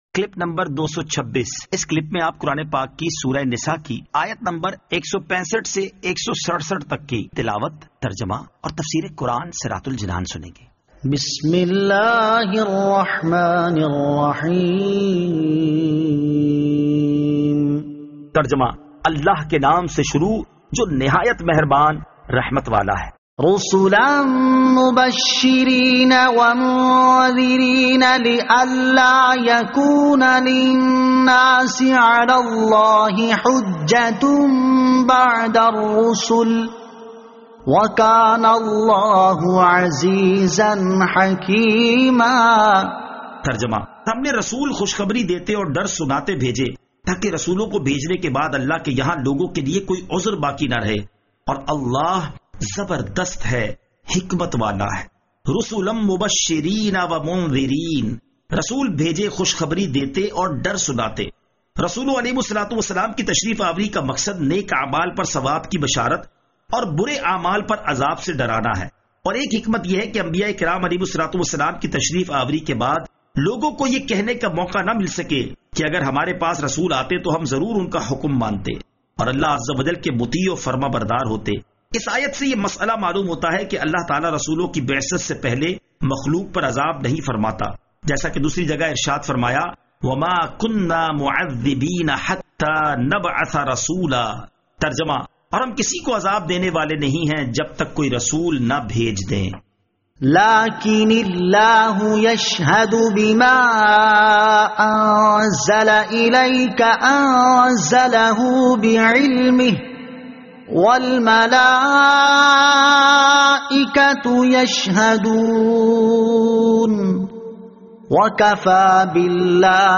Surah An-Nisa Ayat 165 To 167 Tilawat , Tarjama , Tafseer